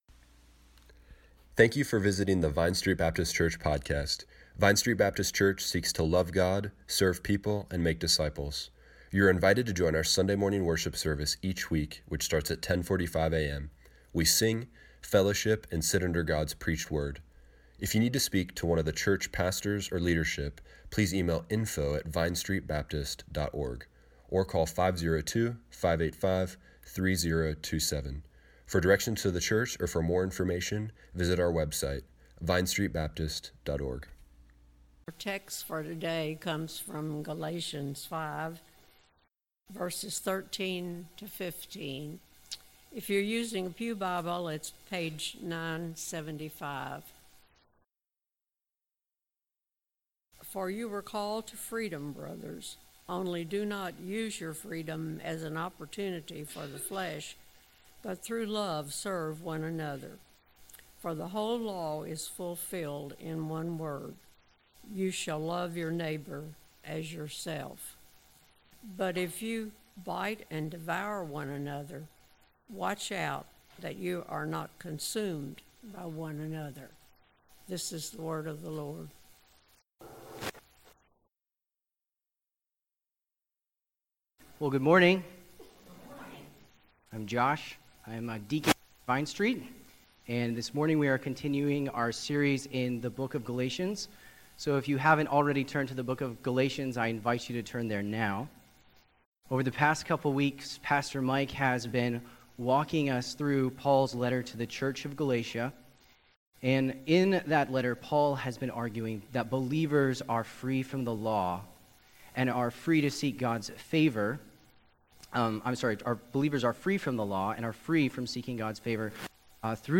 Service Morning Worship
2019 Paul discusses Christian freedom God’s love binds the believer’s heart to the local church The alternative is a food chain Listen to the entire sermon by clicking here .